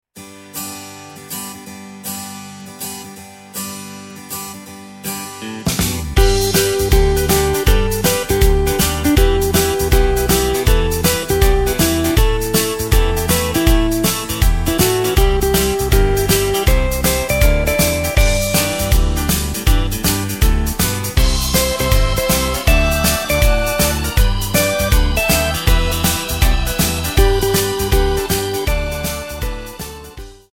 Takt:          4/4
Tempo:         159.00
Tonart:            G
Austropop aus dem Jahr 1981!